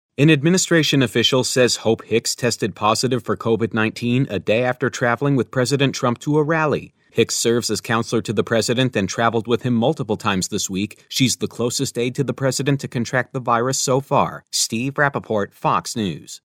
Listen to WBAP/KLIF report with FOX: (Copyright 2020 WBAP/KLIF.